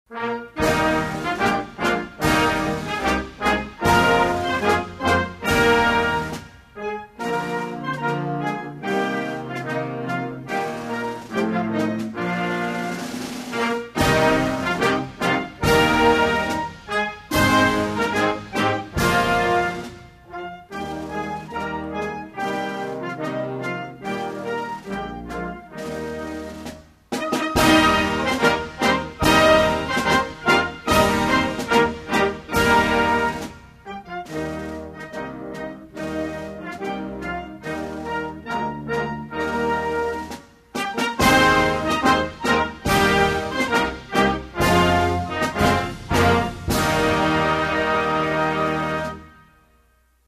National_Anthem_of_Laos.mp3